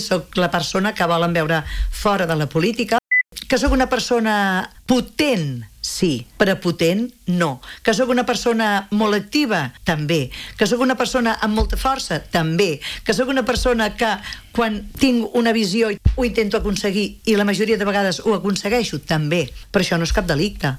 En una entrevista al magazine A l’FM i +, Garrido els ha acusat d’atacar-la políticament i també de manera personal.